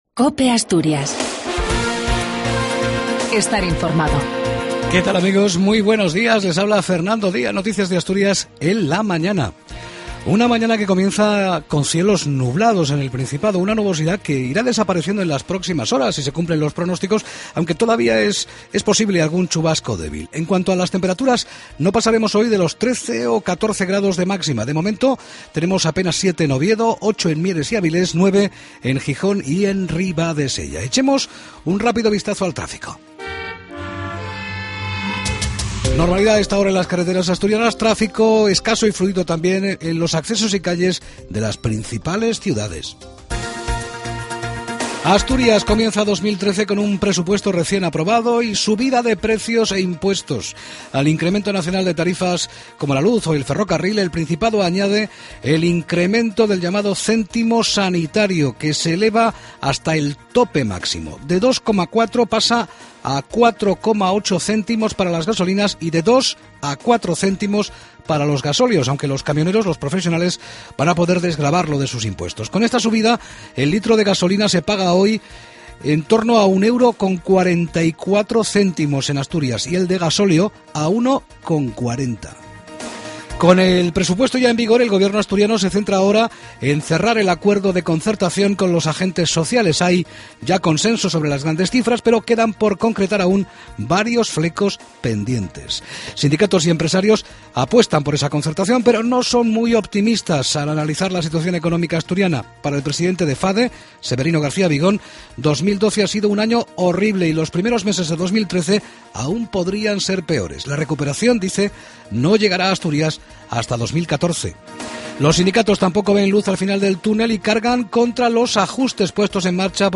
AUDIO: LAS NOTICIAS DE ASTURIAS A PRIMERA HORA DE LA MAÑANA.